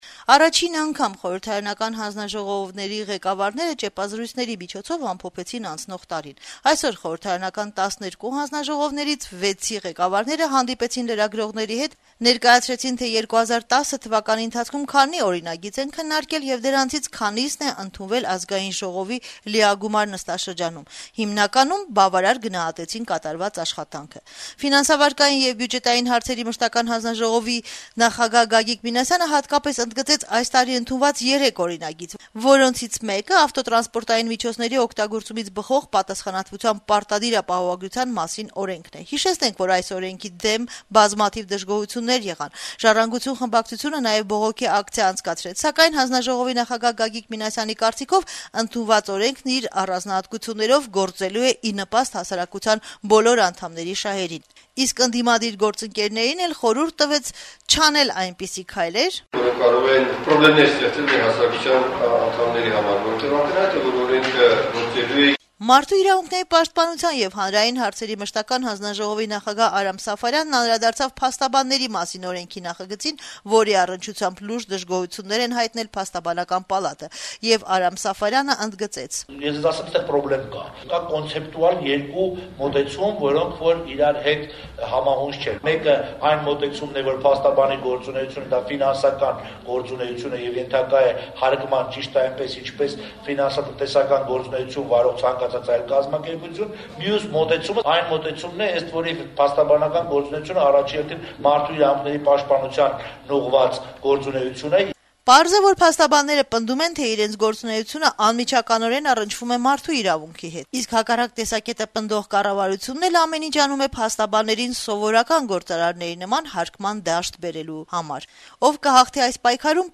Երկուշաբթի օրը խորհրդարանական հանձնաժողովների ղեկավարները ճեպազրույցների միջոցով ամփոփեցին անցնող տարին: